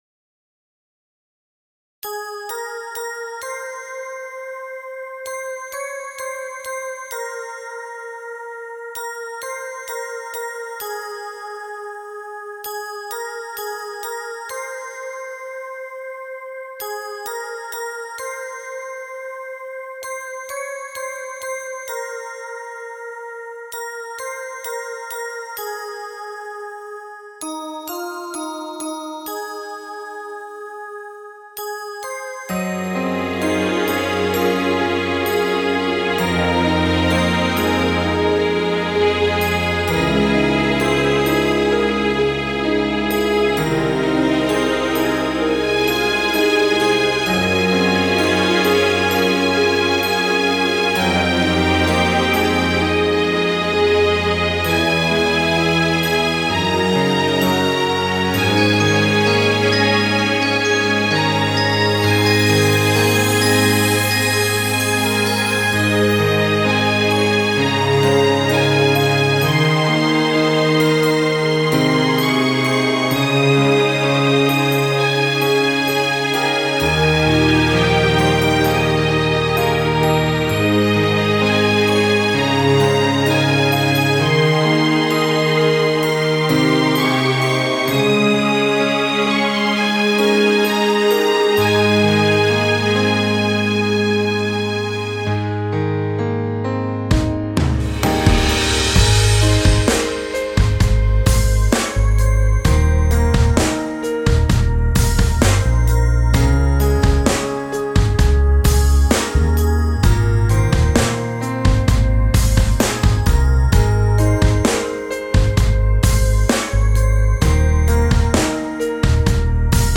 オケ部分修正とボーカルにIAに変更しました